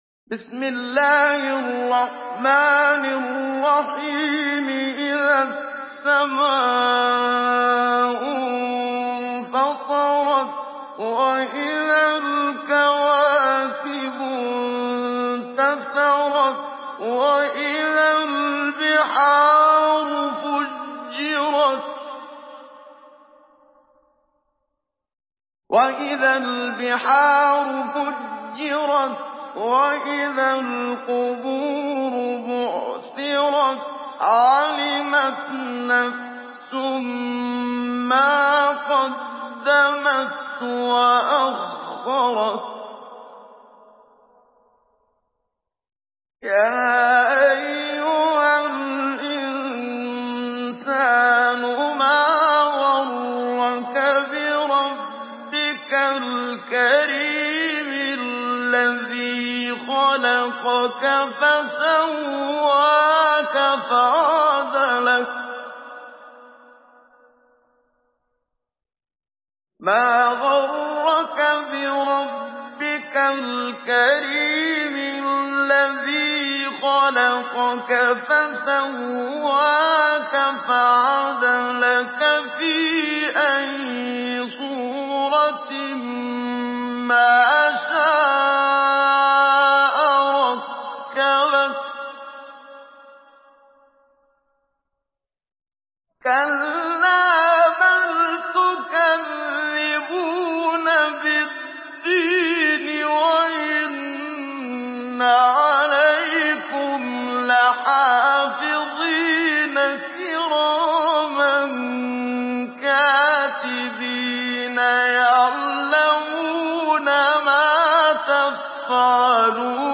Surah الانفطار MP3 by محمد صديق المنشاوي مجود in حفص عن عاصم narration.
مجود